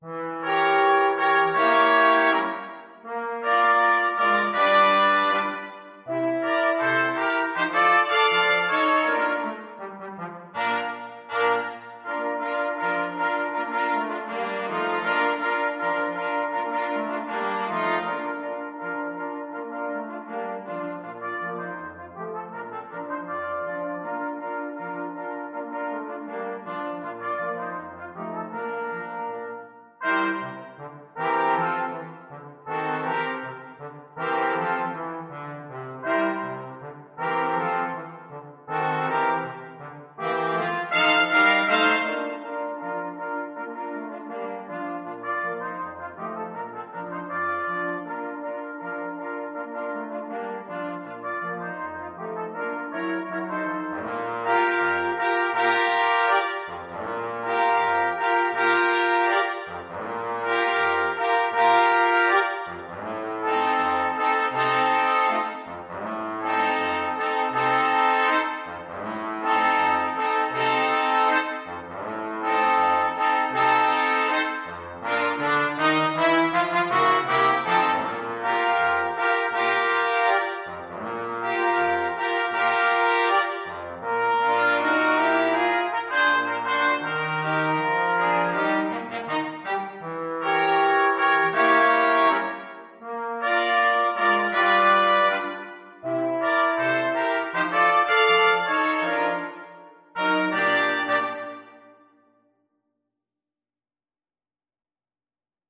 Trumpet 1Trumpet 2French HornTrombone
Allegro Moderato = c. 80 (View more music marked Allegro)
2/2 (View more 2/2 Music)
Brass Quartet  (View more Intermediate Brass Quartet Music)
Jazz (View more Jazz Brass Quartet Music)
Rock and pop (View more Rock and pop Brass Quartet Music)